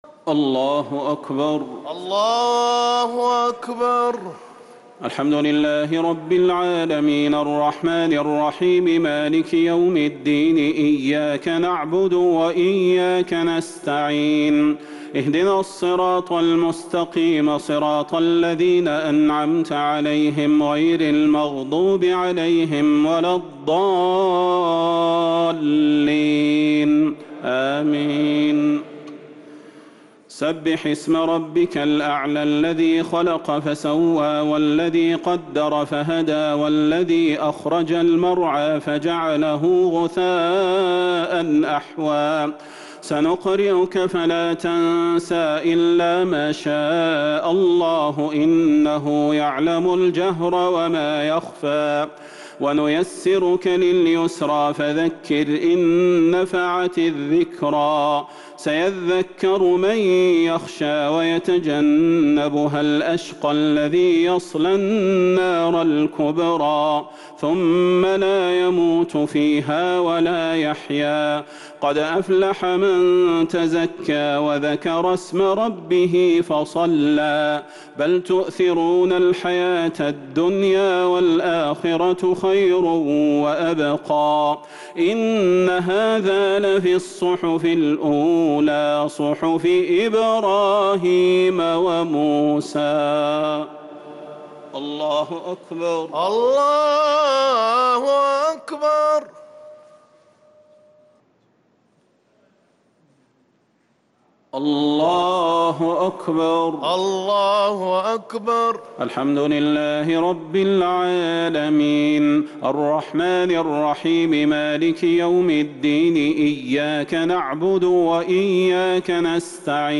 الشفع و الوتر ليلة 5 رمضان 1444هـ | Witr 5 st night Ramadan 1444H > تراويح الحرم النبوي عام 1444 🕌 > التراويح - تلاوات الحرمين